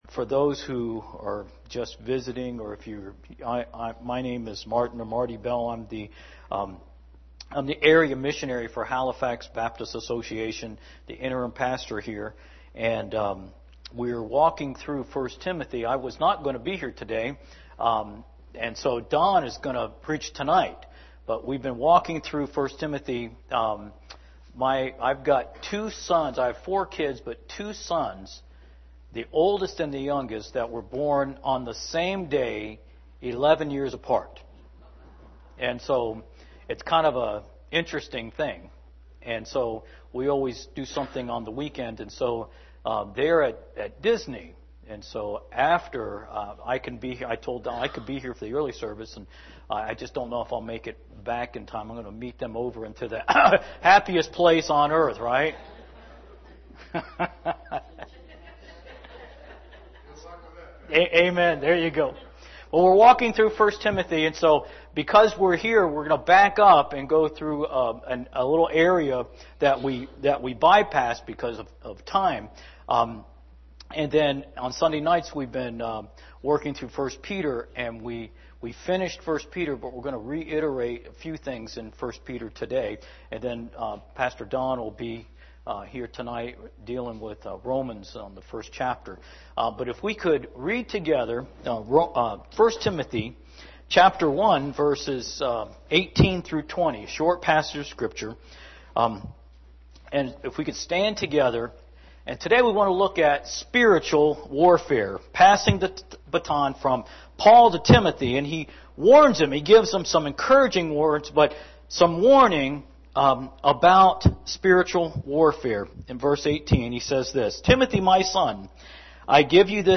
sermon7-28-19am.mp3